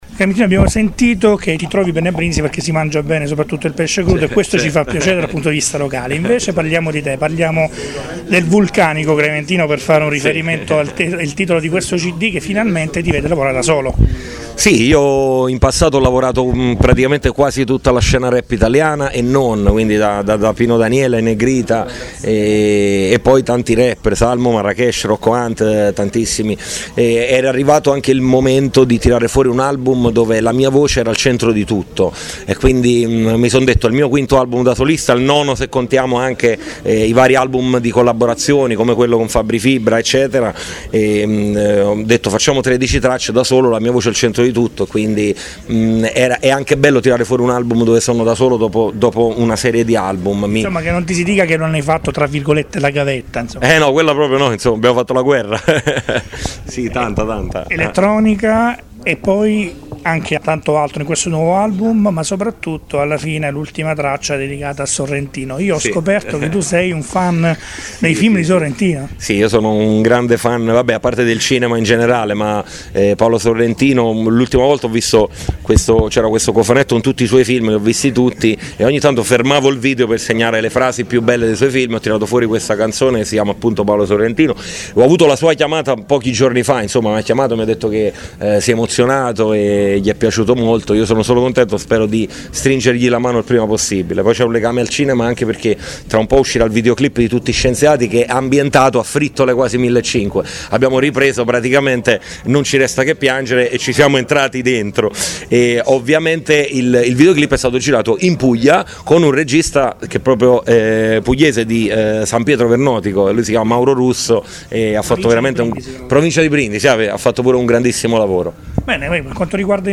intervista_speciale_Clementino.mp3